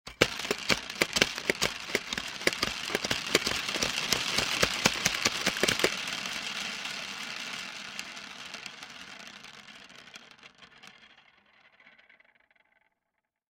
На этой странице собраны звуки юлы — от легкого жужжания до быстрого вращения.
Звук крутящейся пластиковой юлы